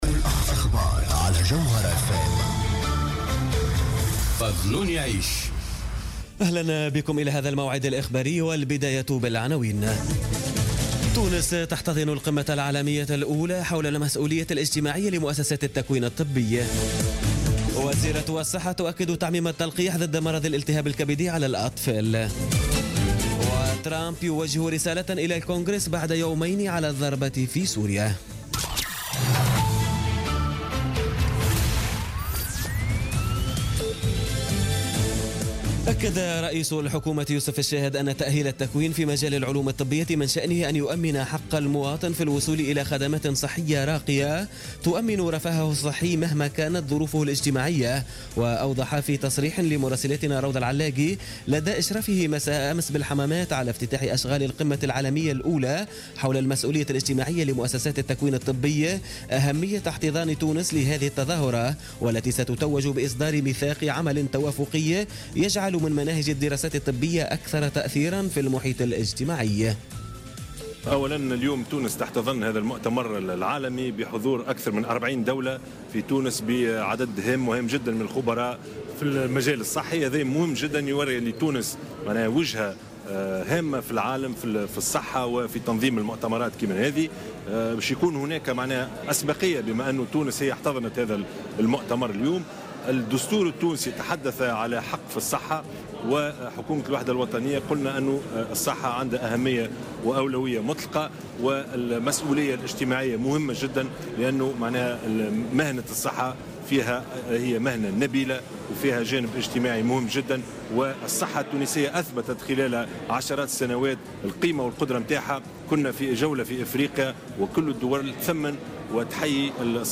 نشرة أخبار منتصف الليل ليوم الأحد 9 أفريل 2017